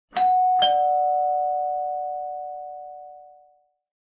Doorbell 3